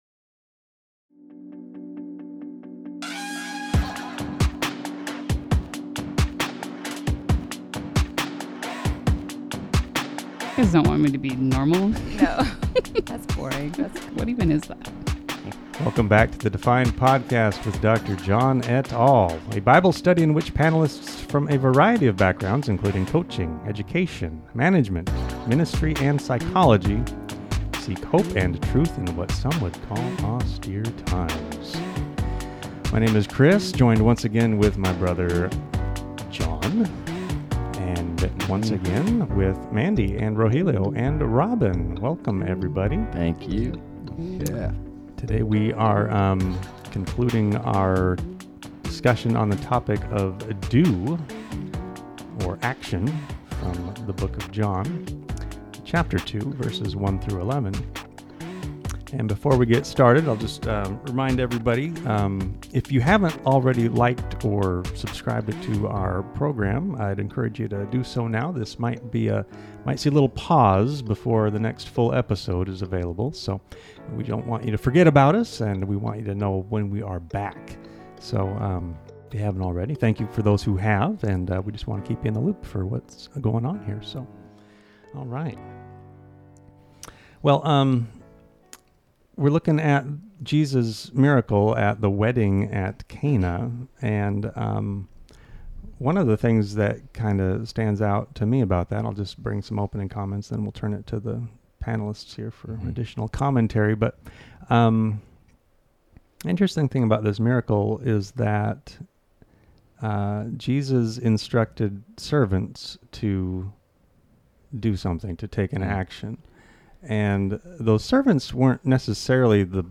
Panelists continue a discussion about the wedding at Cana, the instruction to do whatever Jesus said to do, and its implications for service, obedience, and faith. Scripture: John 2:1-11.